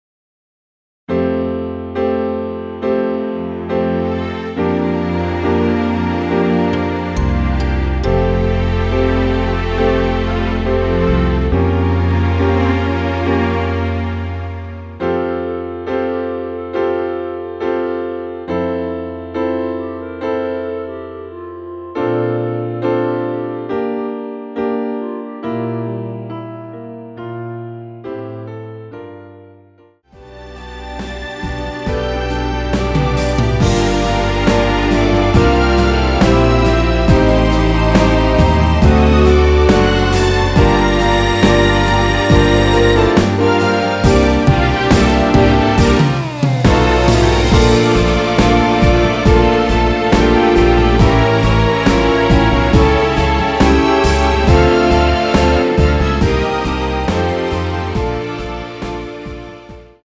원키에서(+1)올린 멜로디 포함된 MR입니다.
앞부분30초, 뒷부분30초씩 편집해서 올려 드리고 있습니다.
중간에 음이 끈어지고 다시 나오는 이유는